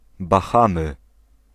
Ääntäminen
Ääntäminen US Tuntematon aksentti: IPA : /bəˈhɑː.məz/ IPA : /bəˈhɑ.məz/ Lyhenteet (laki) Bah.